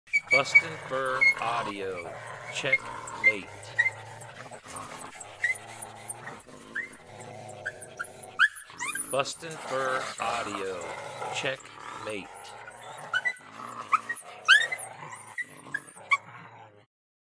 Male and female Coyotes bickering at each other over dominance, the male is the aggressor.